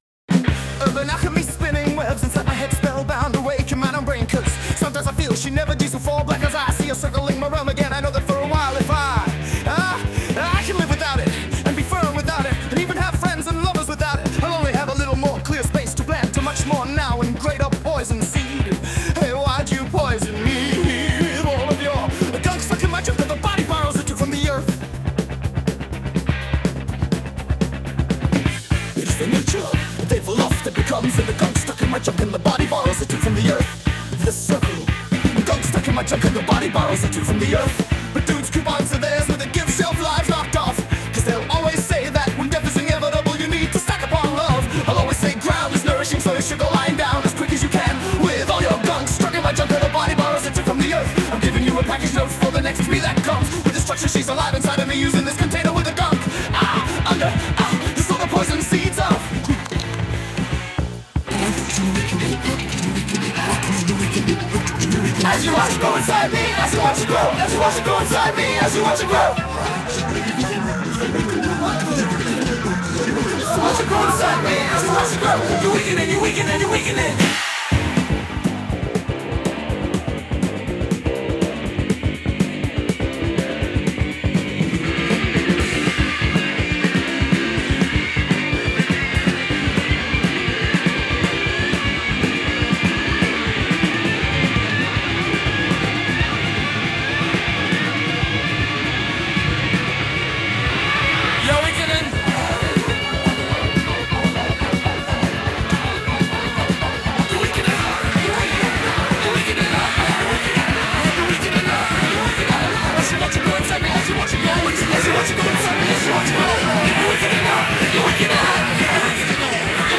English, Punk, Metal, Rock | 18.04.2025 12:52